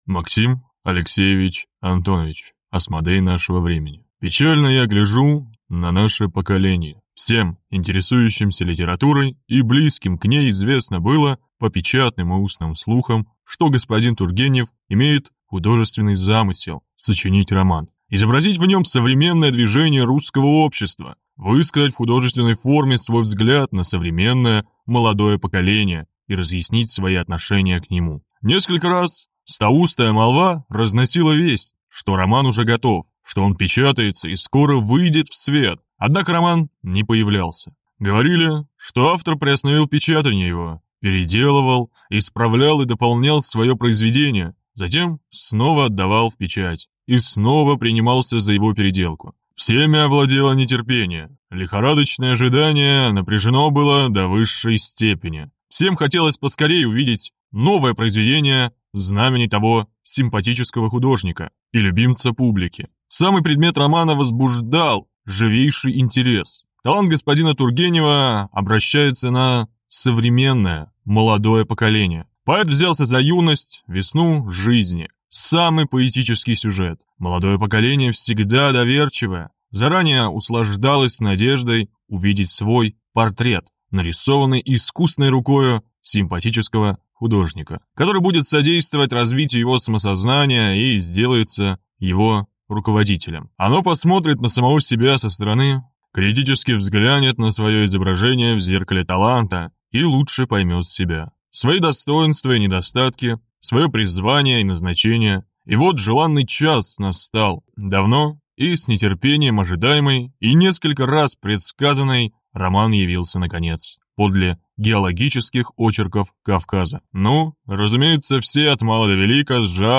Аудиокнига Асмодей нашего времени | Библиотека аудиокниг